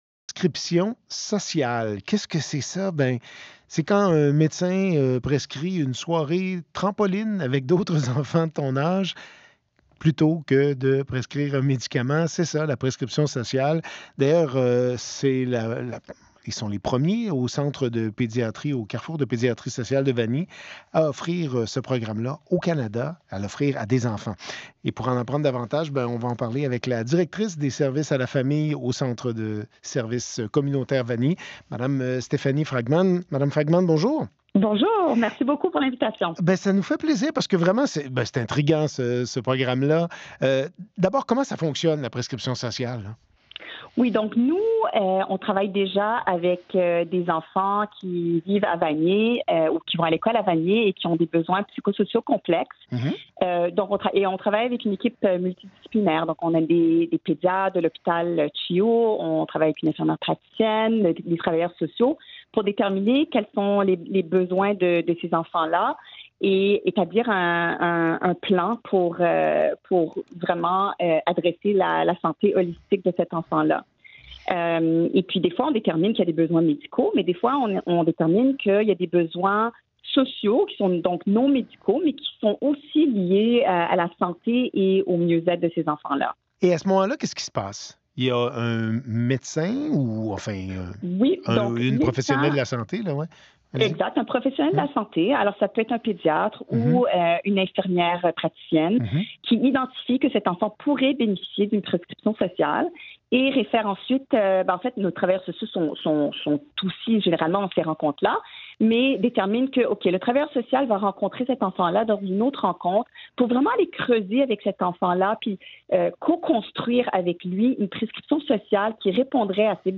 Entrevue
émission de radio Sur le vif, Radio-Canada